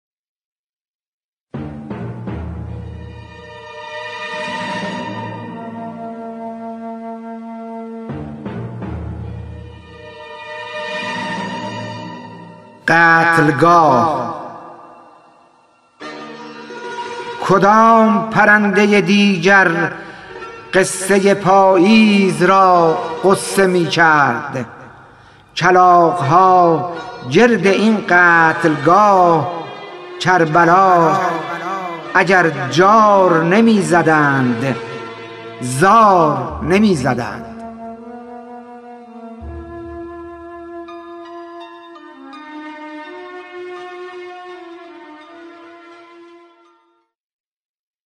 خوانش شعر سپید عاشورایی / ۶